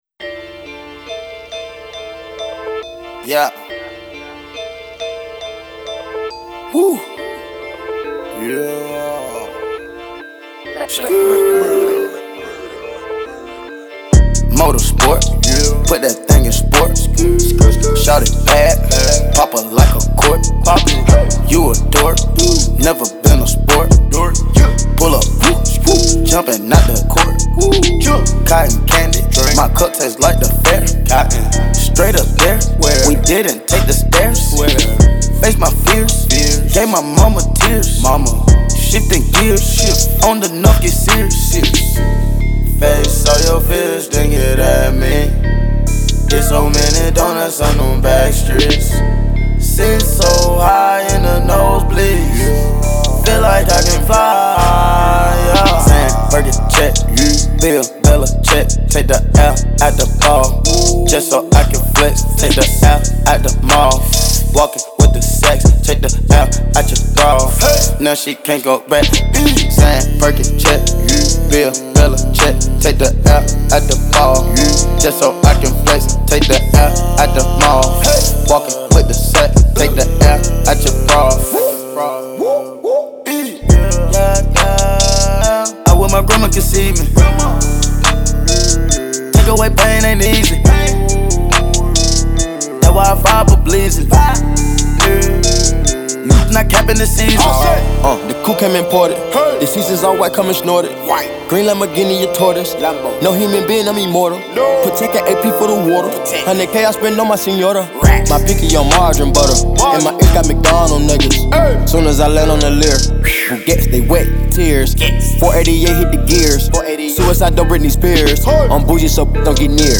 UncategorizedSINGLESHIP-HOP/RAP